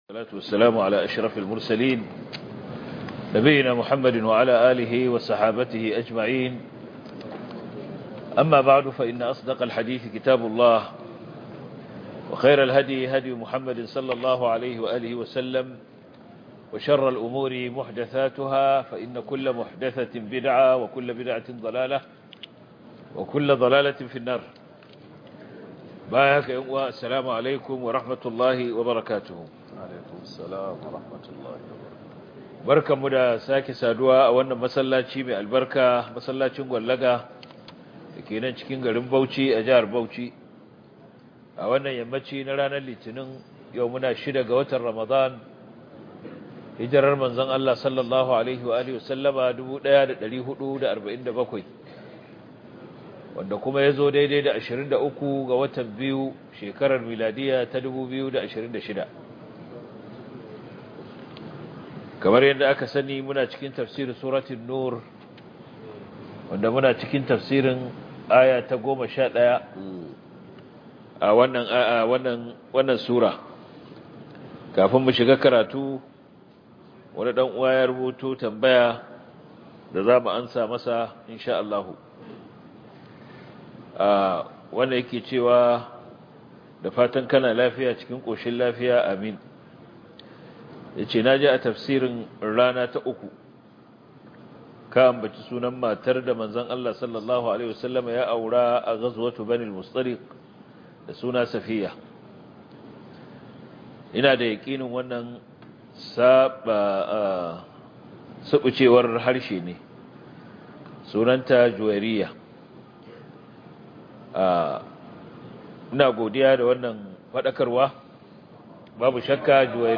← Back to Audio Lectures 06 Ramadan Copied!